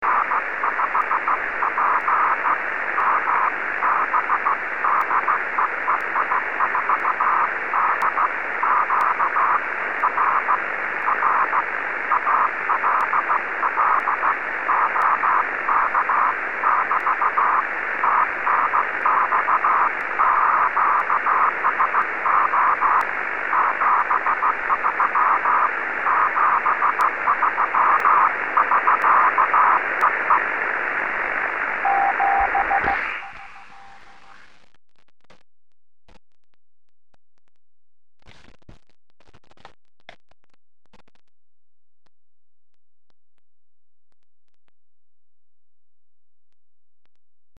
Ouverture aurorale intense fin juillet. Bilan de l'activité depuis JN08XX avec 100W et 2*4el: